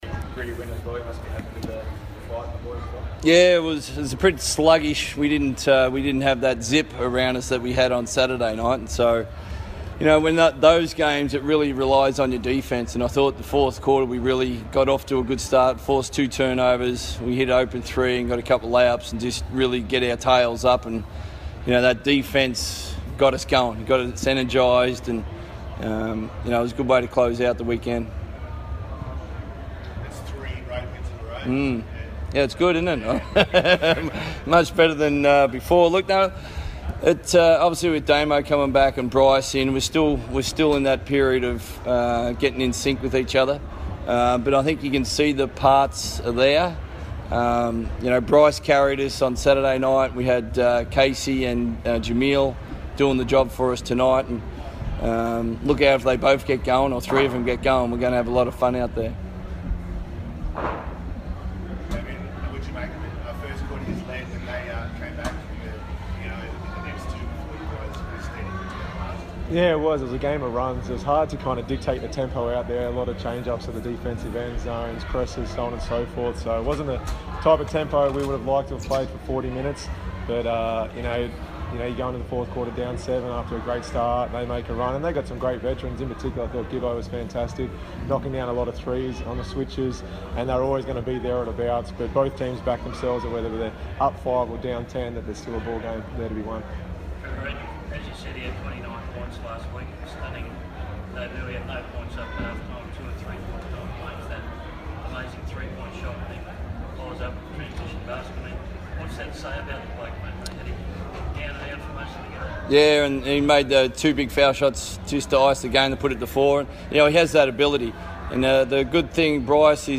speak to the media following the Wildcats away win versus the Brisbane Bullets.